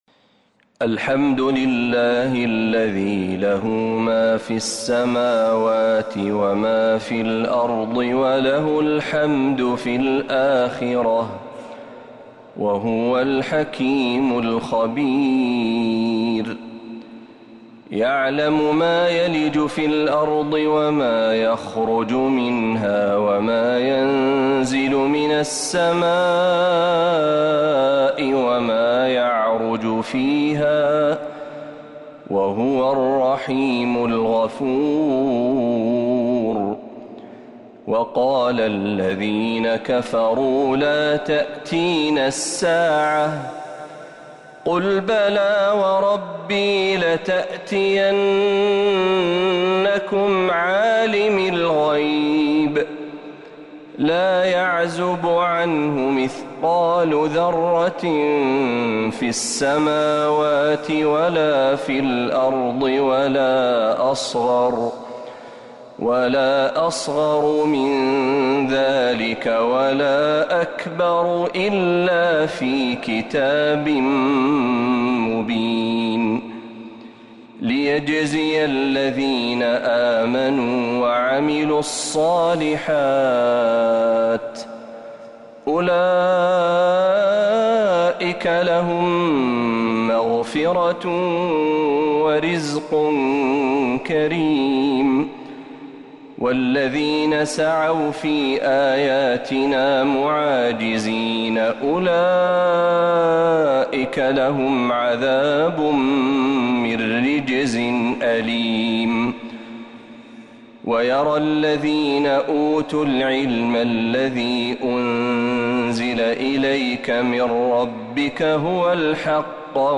سورة سبأ كاملة من الحرم النبوي